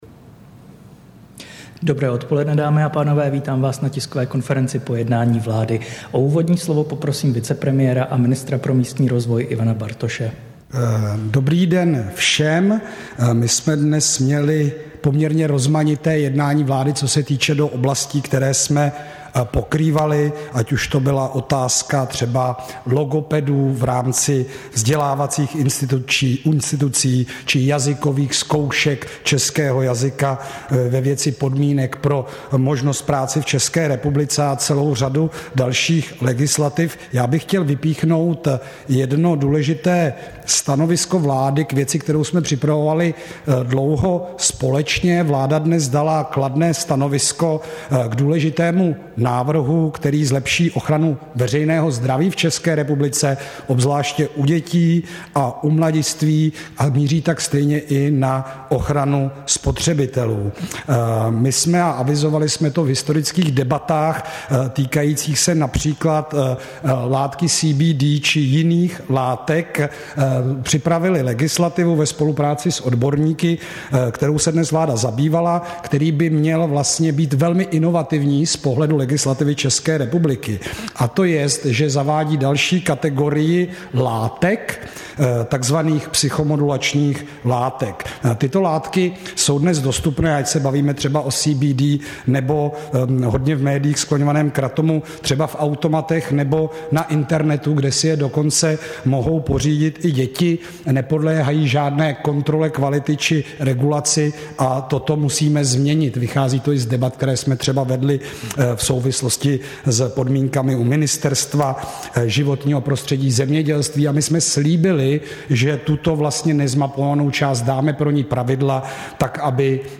Tisková konference po jednání vlády, 30. srpna 2023